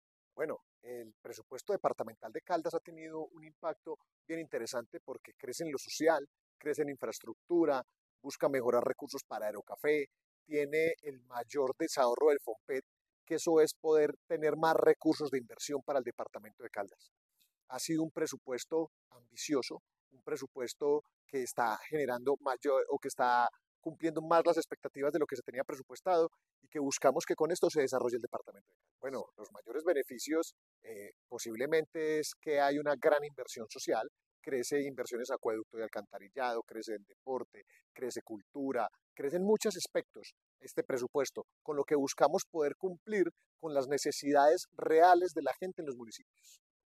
David Islem Ramírez, diputado de Caldas y ponente del proyecto